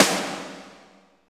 52.01 SNR.wav